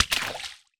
water_splash_small_item_04.wav